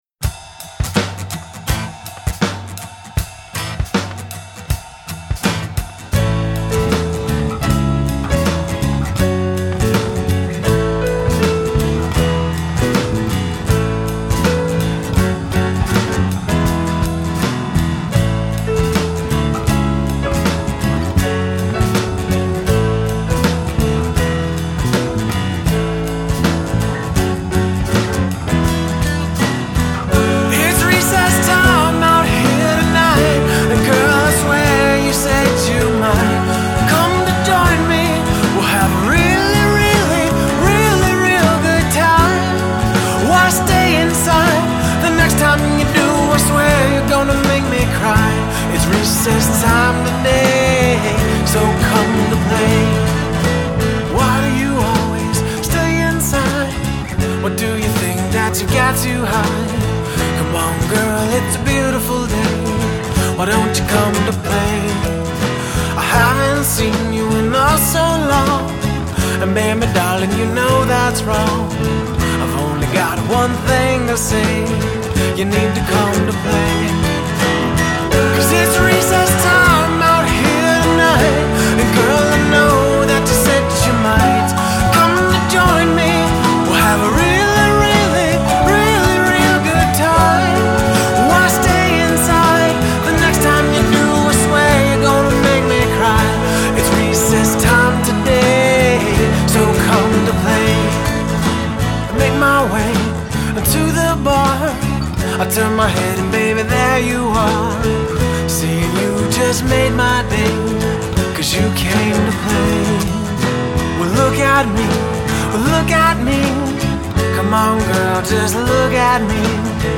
Guitar, Vocals
Drums
Bass Guitar
Piano, Organ